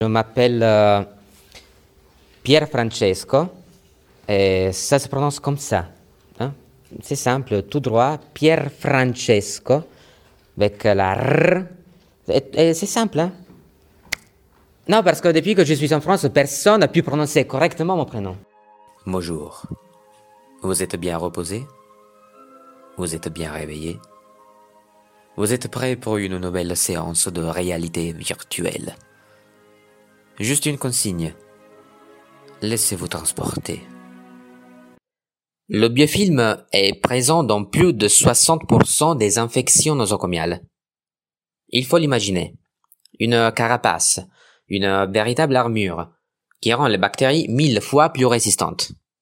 Voix off
25 - 40 ans